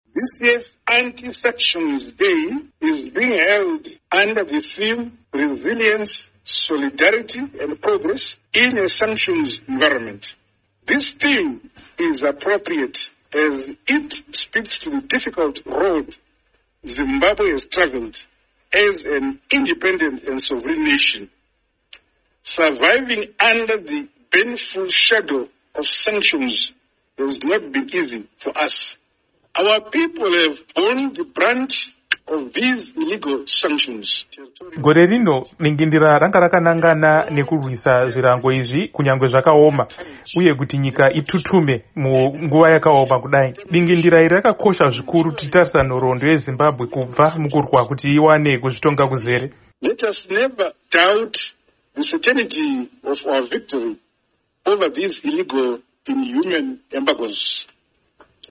VaMnangagwa Vachitaura Pamusoro peSADC